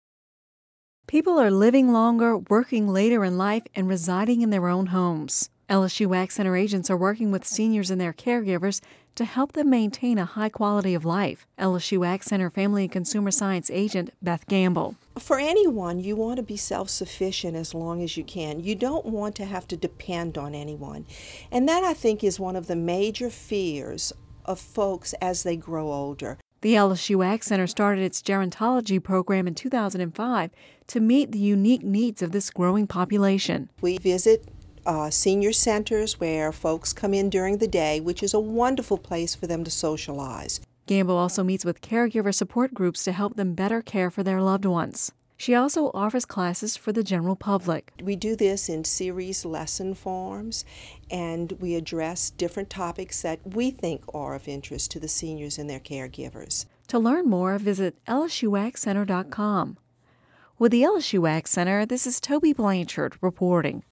(Radio News 02/21/11) People are living longer, working later in life and residing in their homes. LSU AgCenter agents are working with seniors and their caregivers to help them maintain a high quality of life.